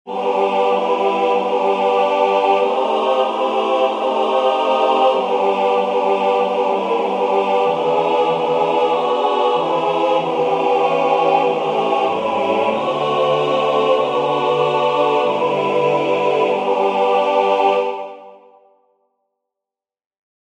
Key written in: G Major
How many parts: 4
Type: Barbershop
All Parts mix: